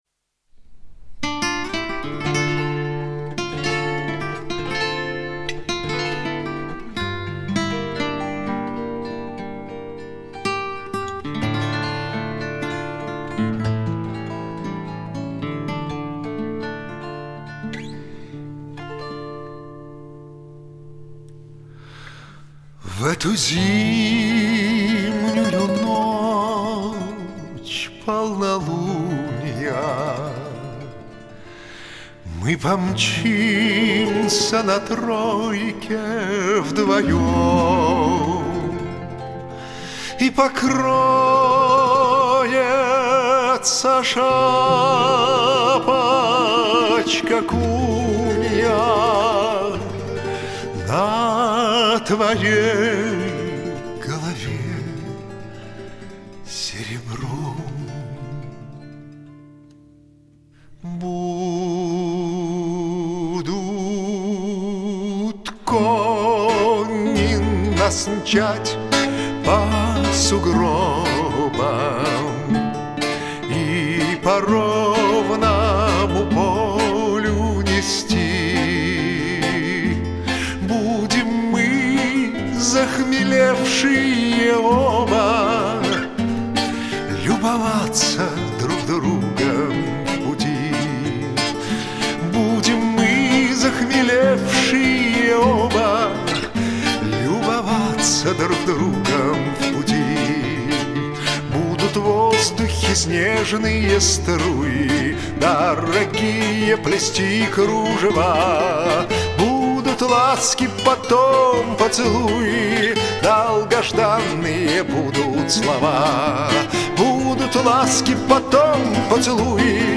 Романсы